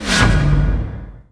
potal_scroll.wav